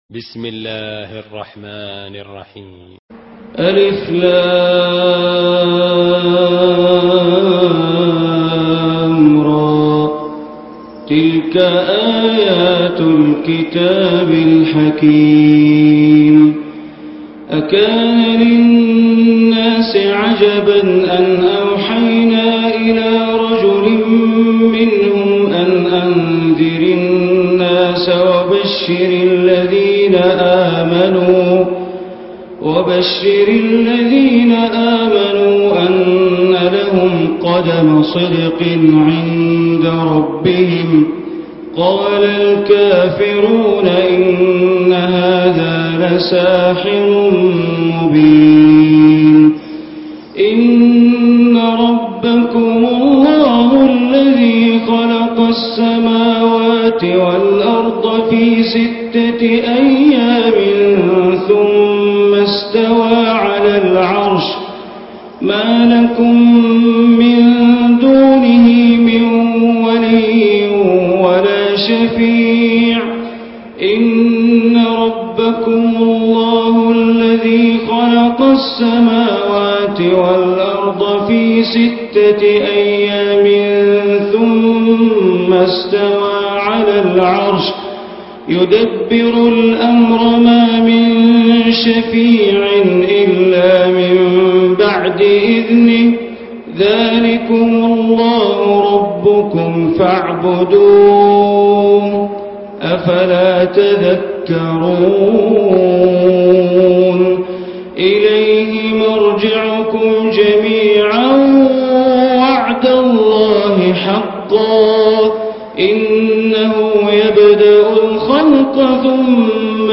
Surah Yunus Recitation by Sheikh Bandar Baleela
Surah Yunus, listen online mp3 tilawat / recitation in Arabic in the beautiful voice of Imam e Kaaba Sheikh Bandar Baleela.
10-surah-yunus.mp3